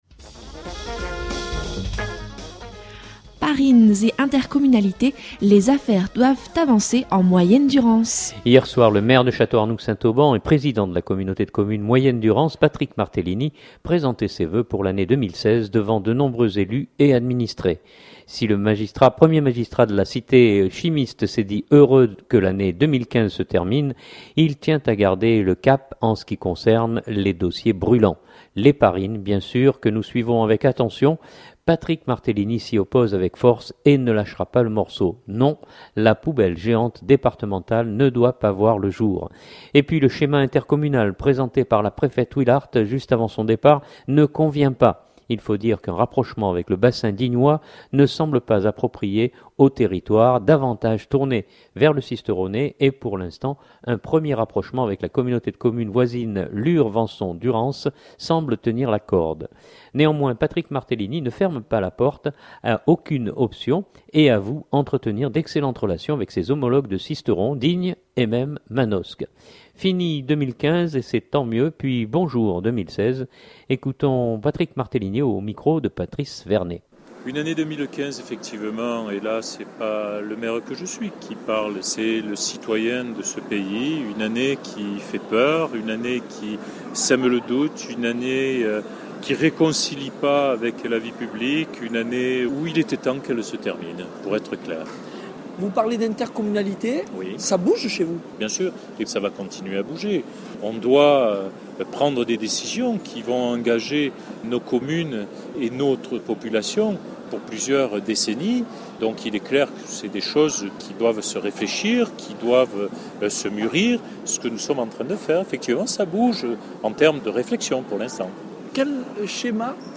Hier soir le Maire de Château-Arnoux St Auban et Président de la Communauté de Communes Moyenne Durance, Patrick Martellini présentait ses vœux pour l’année 2016 devant de nombreux élus et administrés.